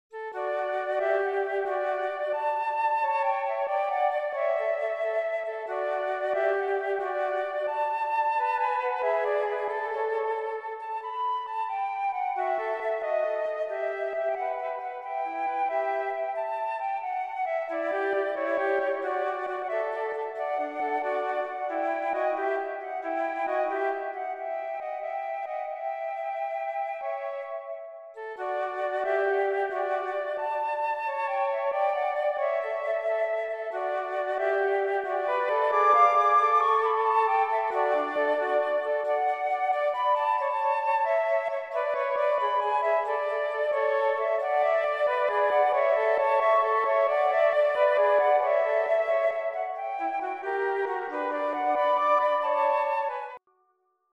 für 2 Flöten
Andantino